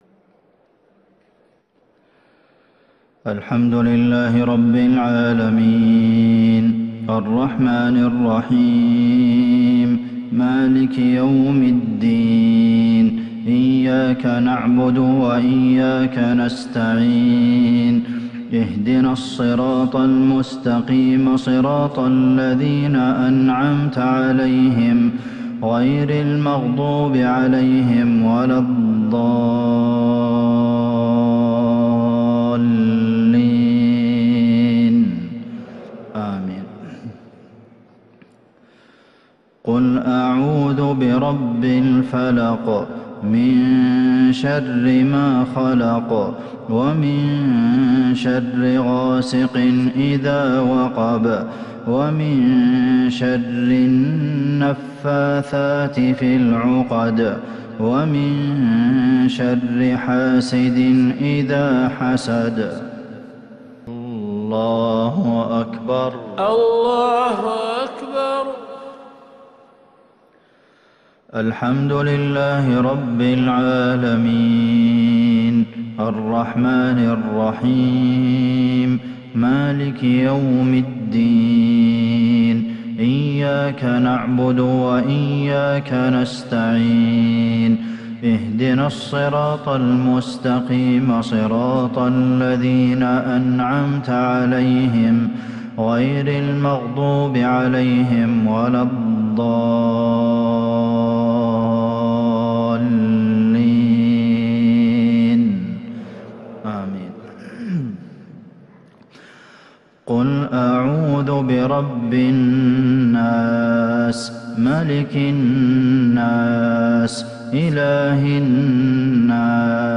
مغرب السبت 4-5-1442هـ سورتي الفلق والناس | Maghrib prayer Surah Al-Falaq and An-Nas 19/12/2020 > 1442 🕌 > الفروض - تلاوات الحرمين